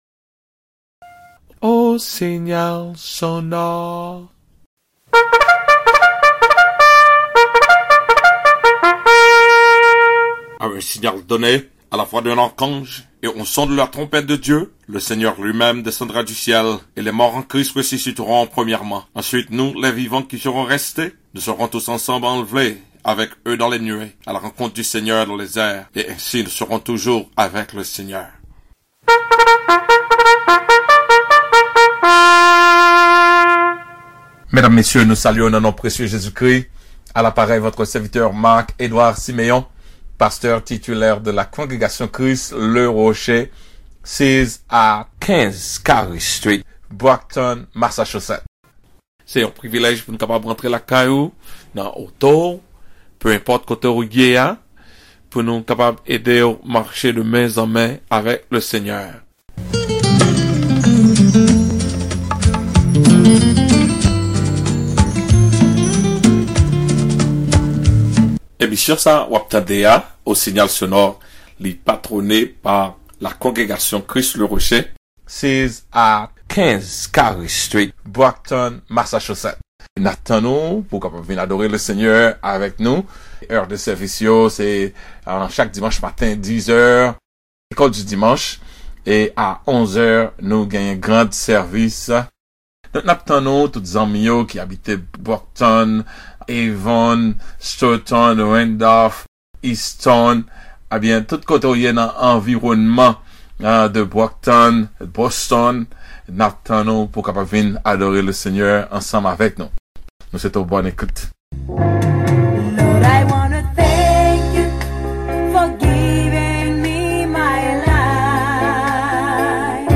CLICK ABOVE FOR THE CREOLE VERSION CUT FOR THE RADIO